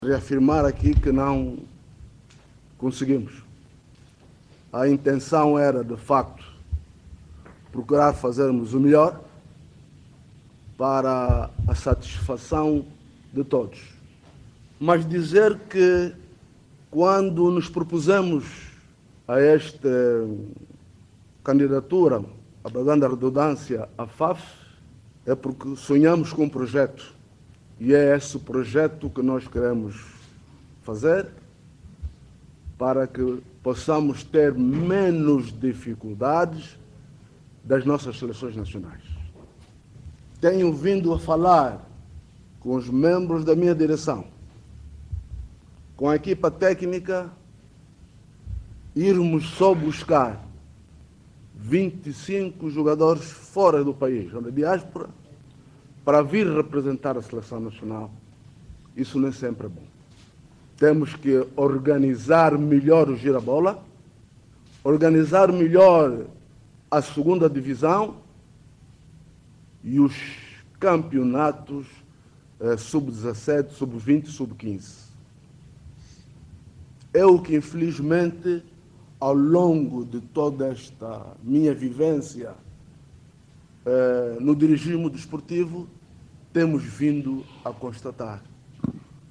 A Federação Angolana de Futebol realizou, hoje, uma conferência de imprensa na sua sede, localizada no Projecto Nova Vida, para fazer o balanço da participação dos Palancas Negras no Campeonato Africano das Nações, no Marrocos, onde a selecção nacional foi eliminada na fase de grupos.